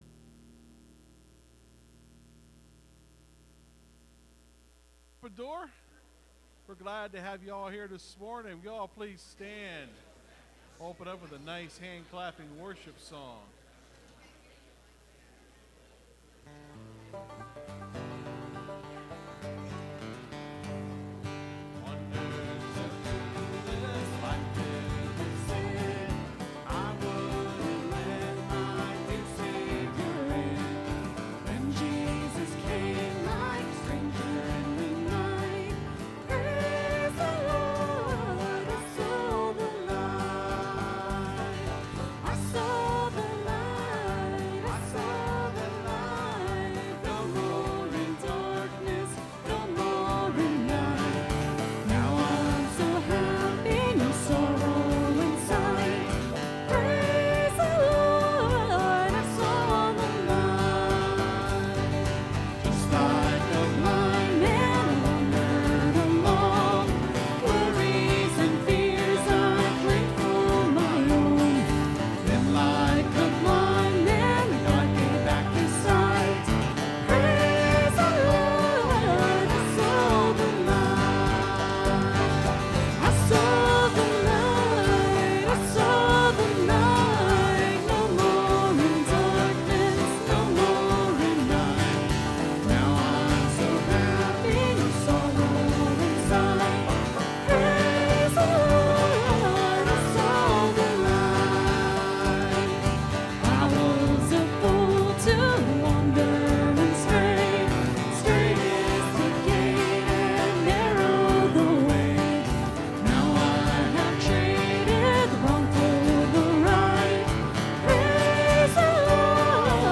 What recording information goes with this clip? (Sermon starts at 24:25 in the recording).